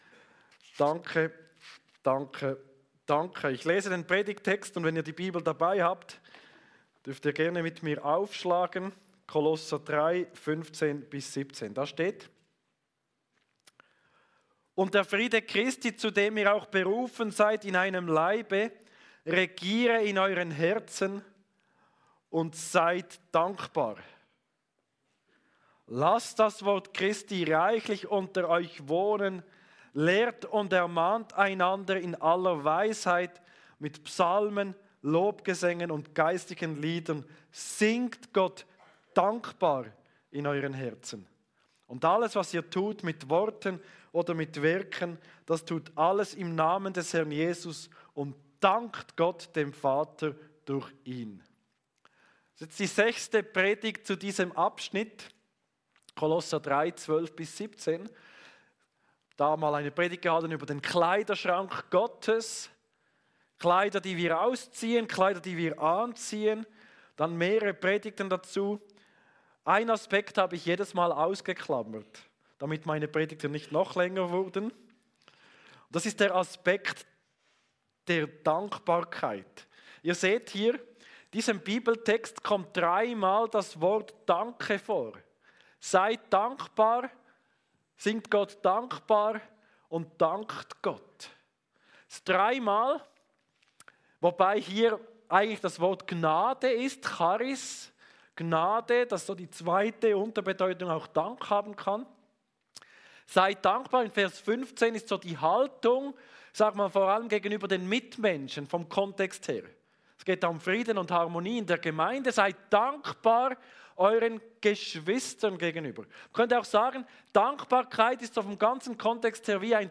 Aktuelle Predigt